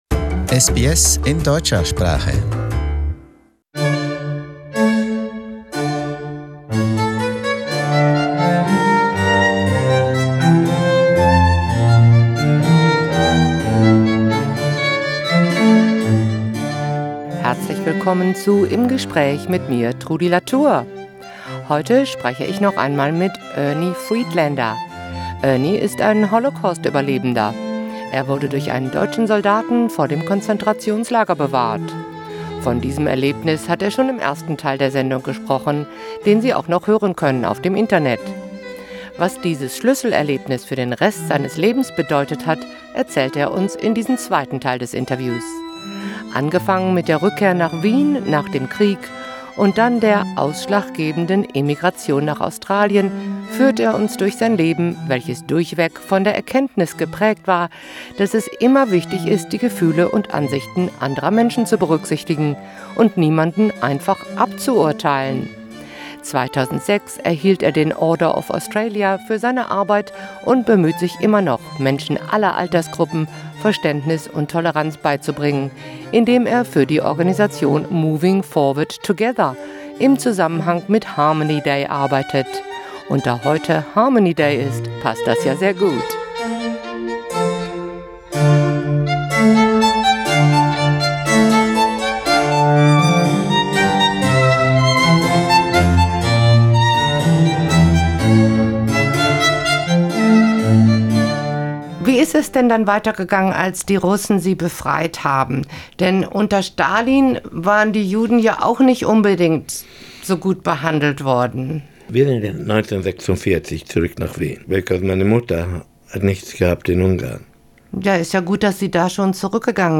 In conversation: from Holocaust survivor to OAM recipient